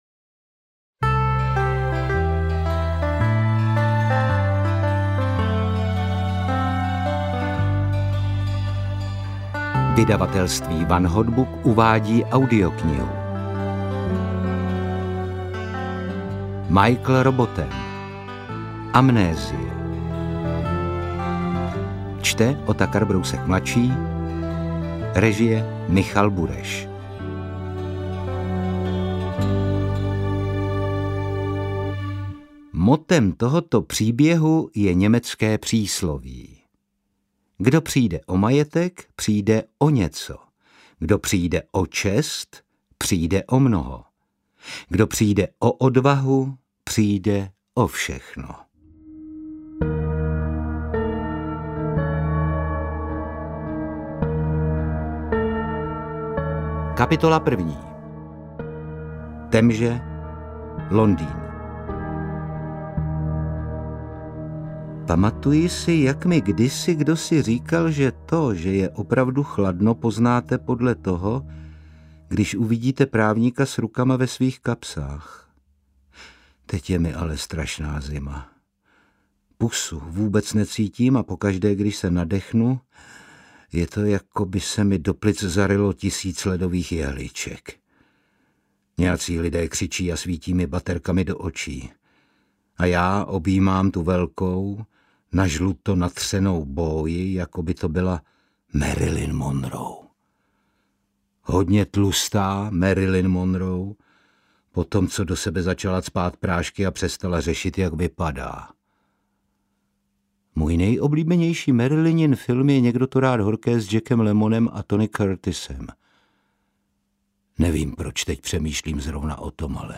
Interpret:  Otakar Brousek ml.
AudioKniha ke stažení, 52 x mp3, délka 13 hod. 43 min., velikost 739,0 MB, česky